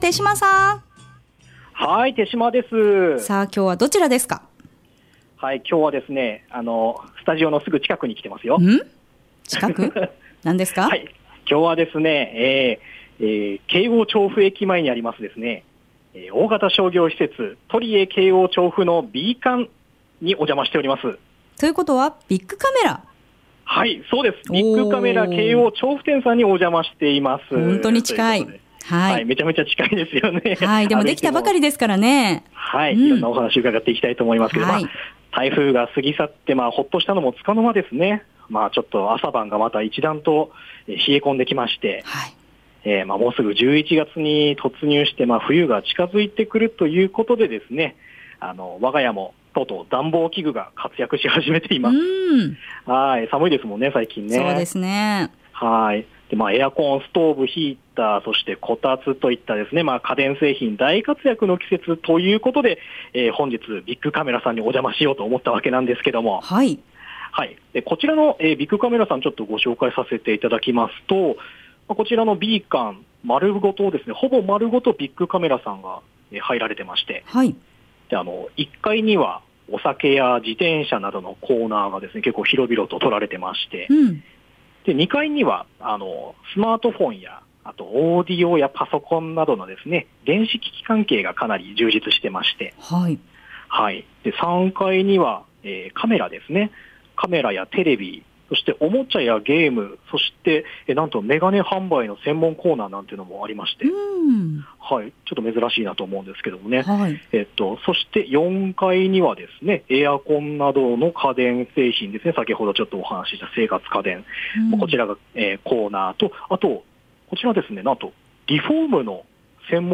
放送音声はこちらです～↓ 午後のカフェテラス 街角レポート 2017-10-26(木) はい、父親譲りの家電好きな私なので、オープン前からどんな店舗になるのかな？